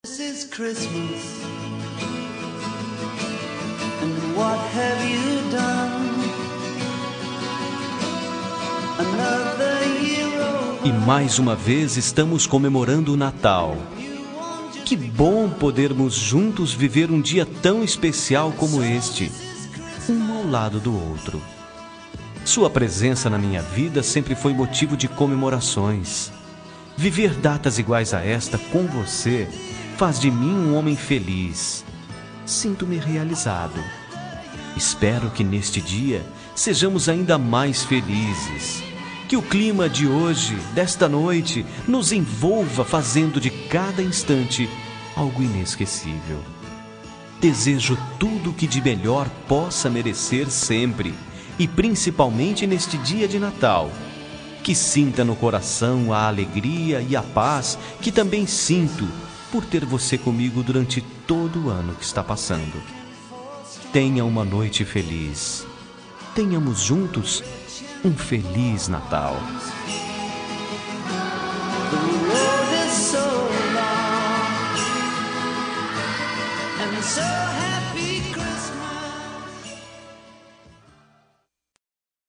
Natal Romântico – Voz Masculina – Cód: 34793